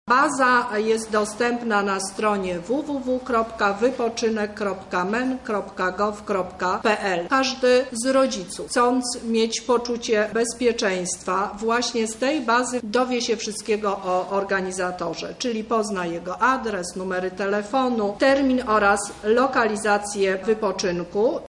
– mówi Teresa Misiuk, Lubelski Kurator Oświaty.